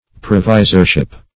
Search Result for " provisorship" : The Collaborative International Dictionary of English v.0.48: Provisorship \Pro*vi"sor*ship\, n. The office or position of a provisor.